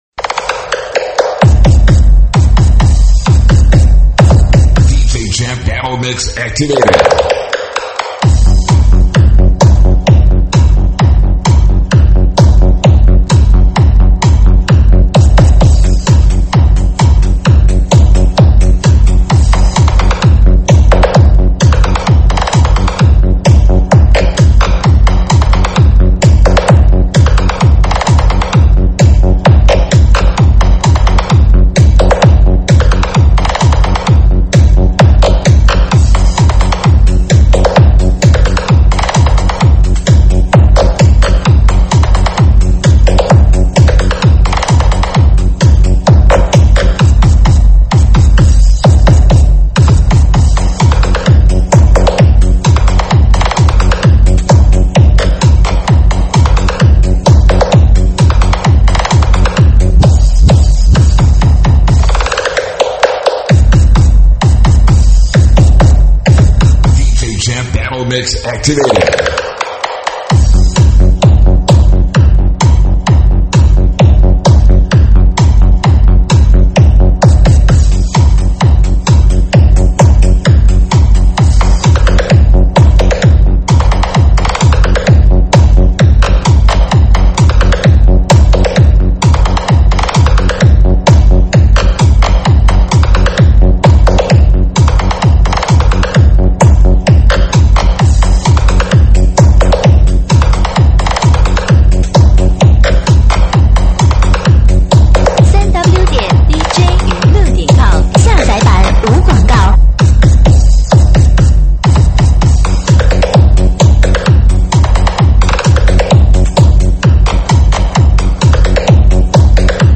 舞曲类别：慢摇舞曲